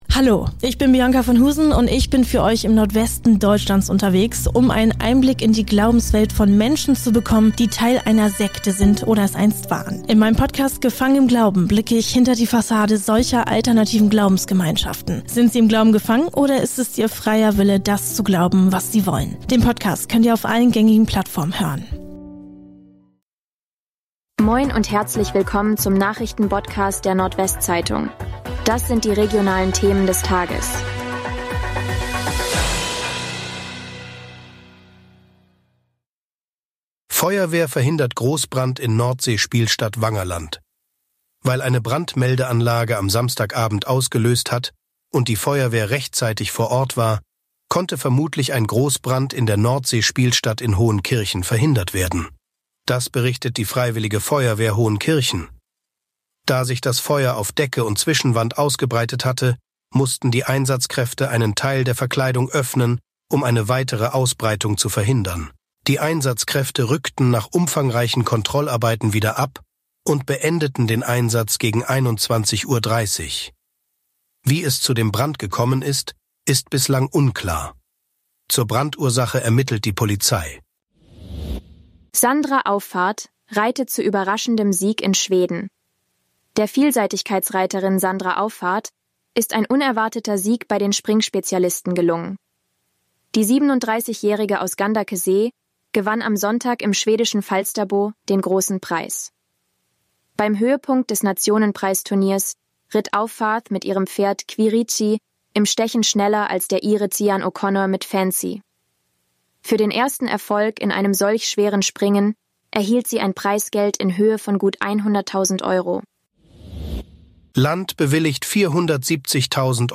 NWZ Nachrichten Botcast – der tägliche News-Podcast aus dem Norden
Nachrichten